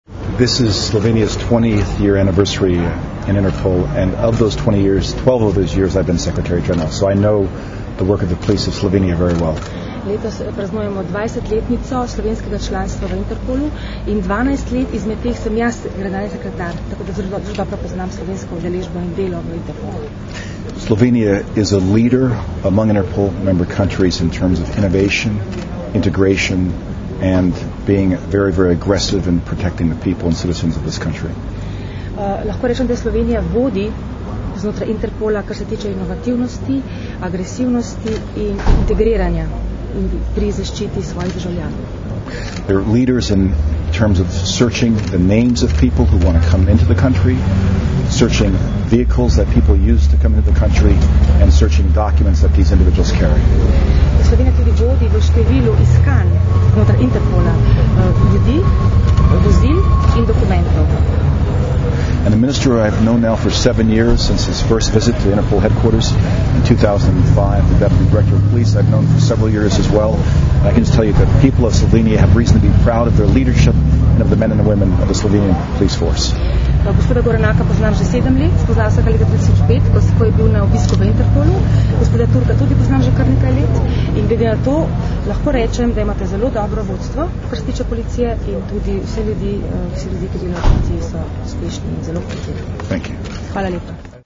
Zvočni posnetek izjave za medije Ronalda K. Nobla (s prevodom) (mp3)